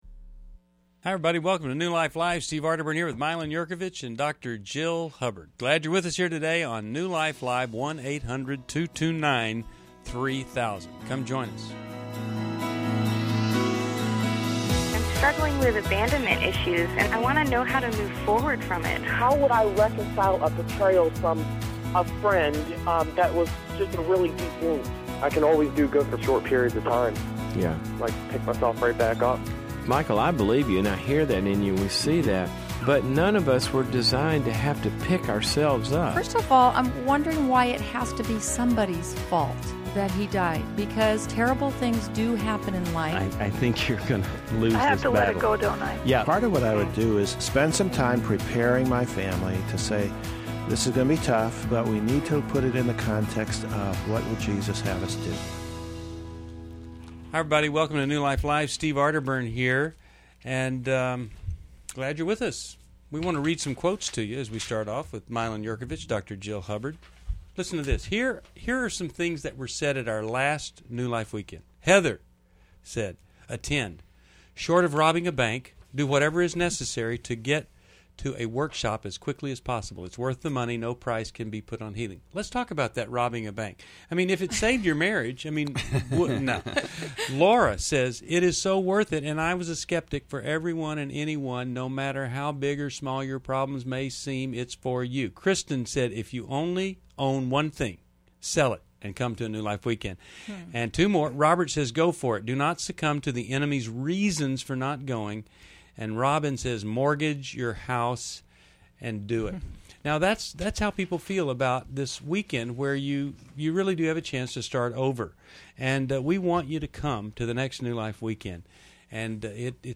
New Life Live: May 27, 2011 - Join hosts as they tackle tough topics like infidelity, unemployment, and marriage recovery through caller insights and expert advice.